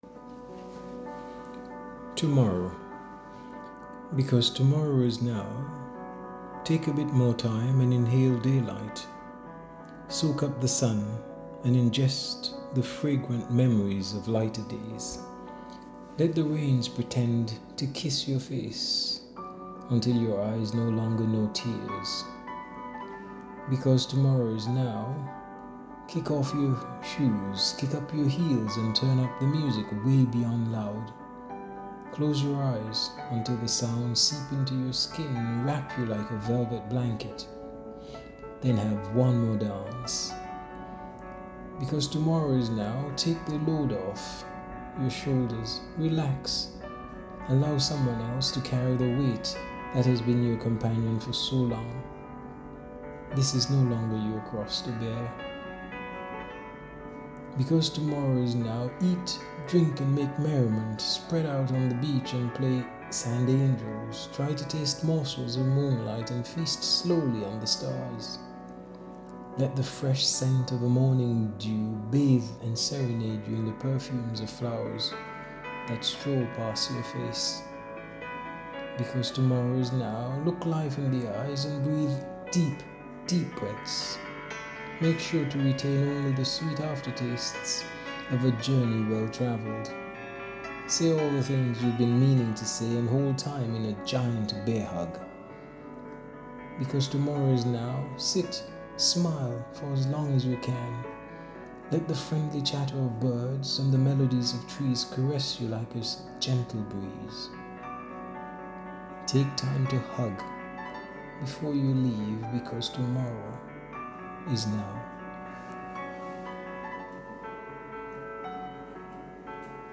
Tomorrow-poem-.mp3